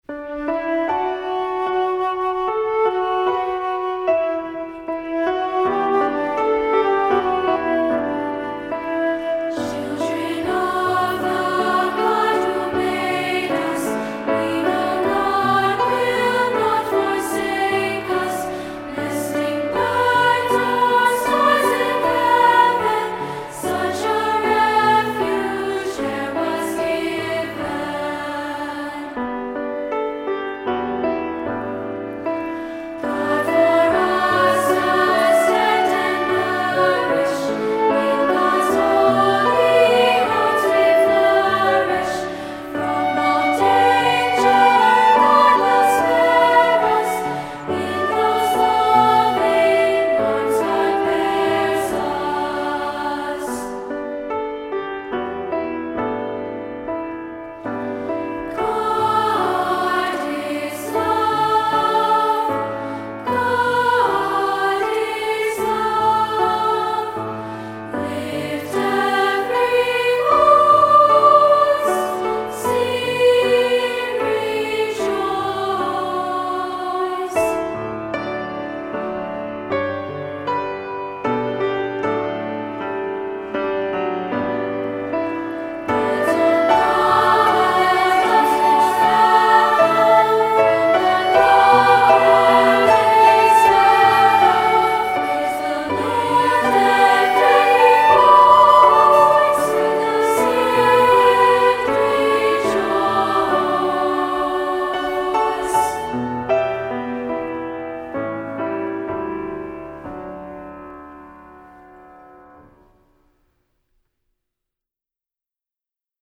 Composer: Swedish Folk Melody
Voicing: 2-Part and Piano